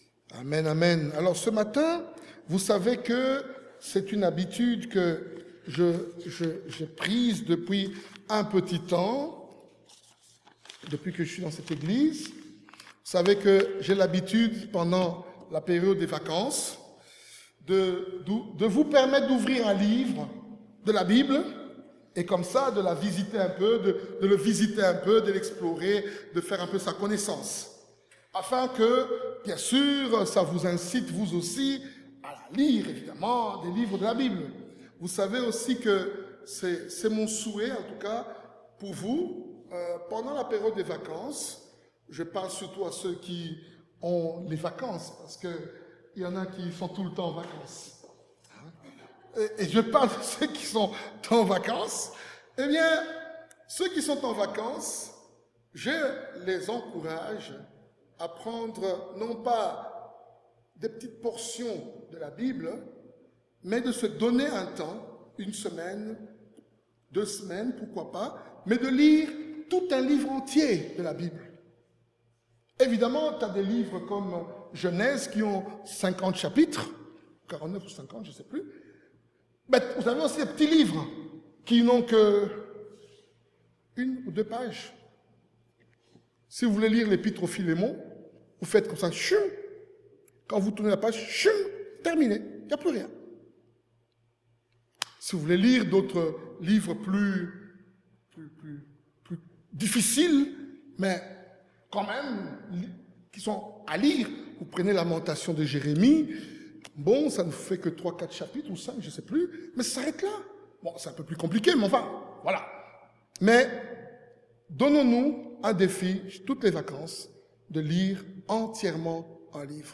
Type De Service: Dimanche matin